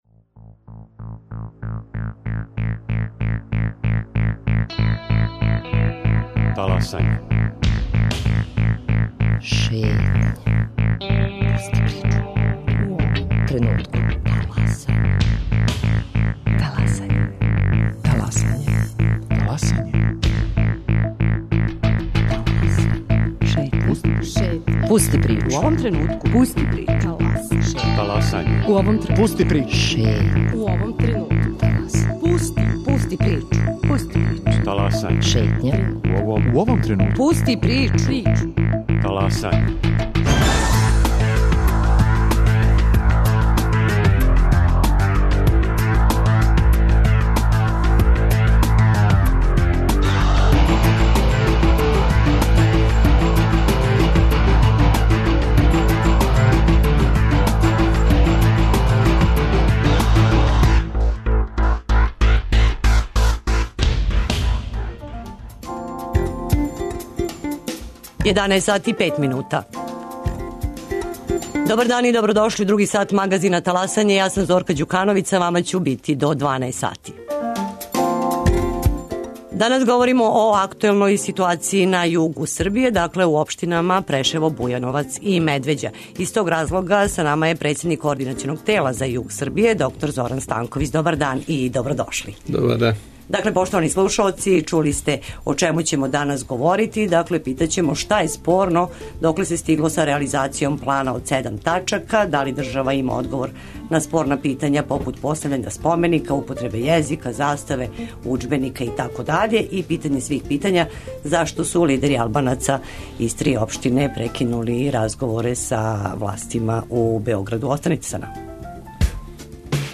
Гост др Зоран Станковић, председник Координационог тела за југ Србије.